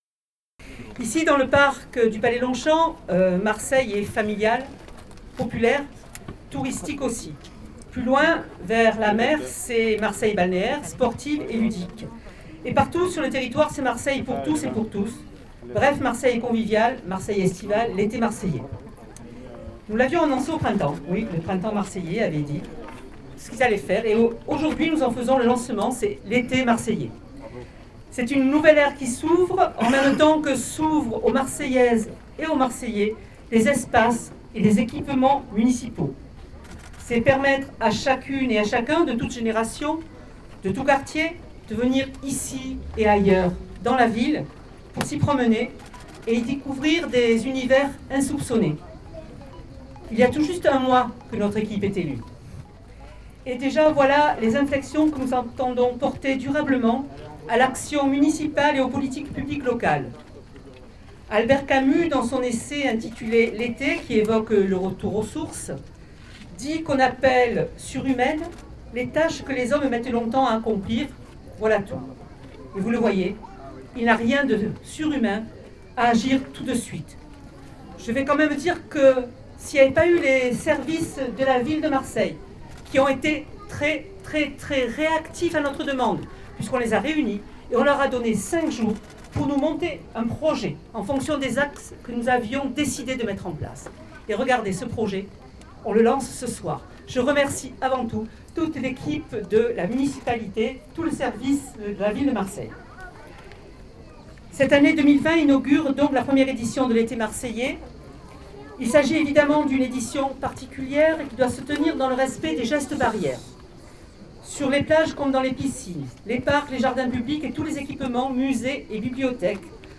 La maire de Marseille Michèle Rubirola vient de lancer au parc Longchamp
michele_rubirola_discours_ete_marseillais_28_7_20.mp3